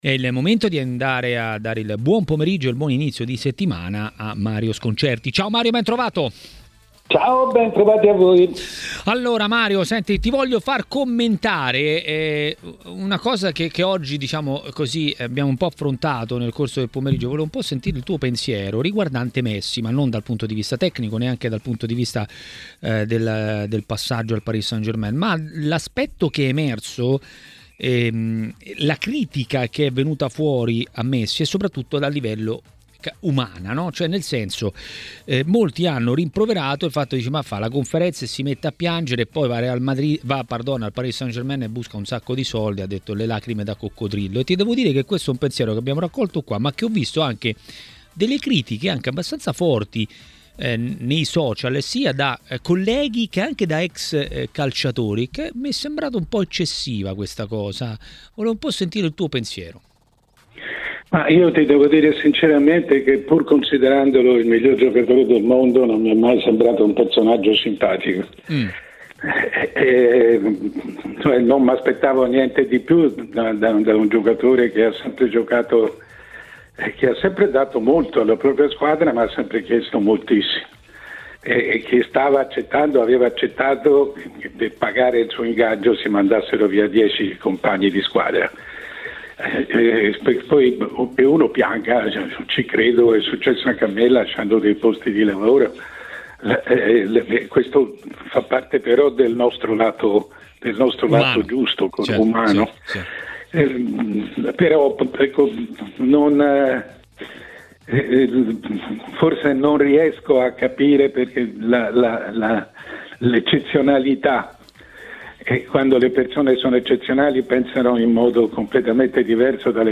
A dire la sua sui movimenti di mercato a TMW Radio, durante Maracanà, è stato il direttore Mario Sconcerti.